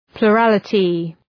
Προφορά
{plʋ’rælətı} (Ουσιαστικό) ● πλειονοψηφία